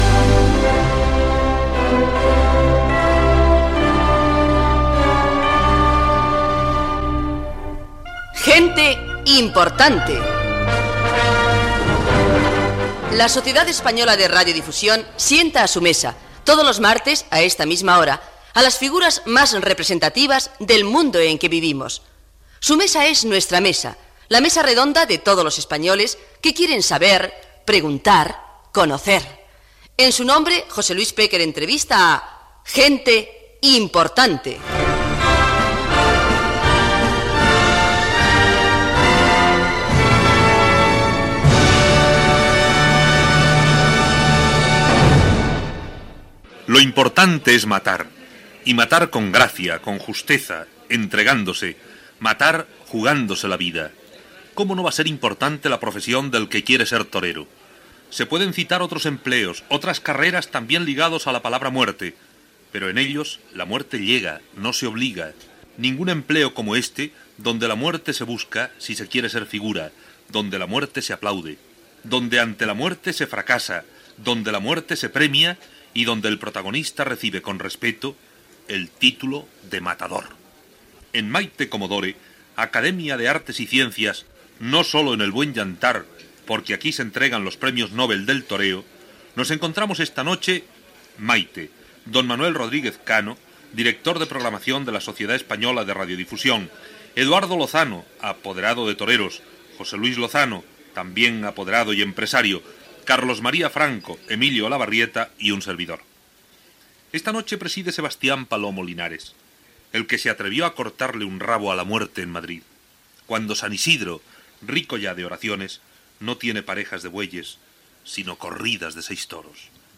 Careta del programa, la importància de la feina de ser torero, entrevista al torero Sebastián Palomo Linares
Gènere radiofònic Entreteniment